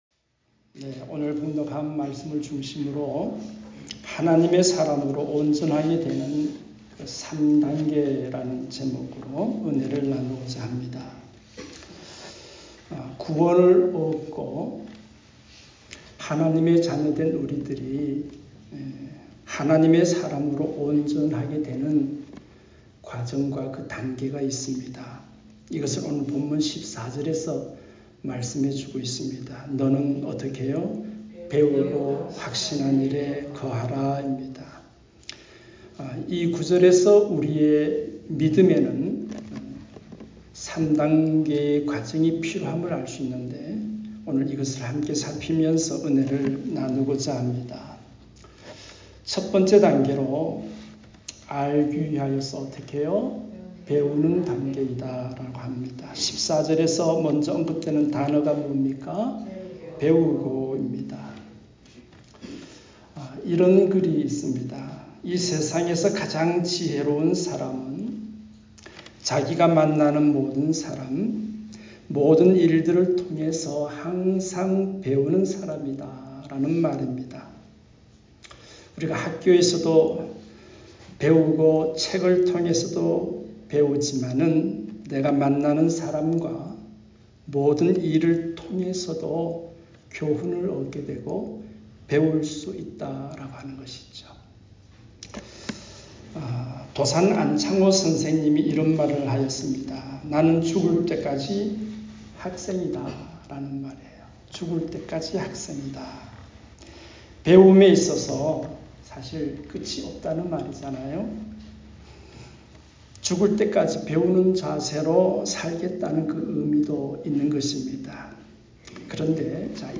주일음성설교 에 포함되어 있습니다.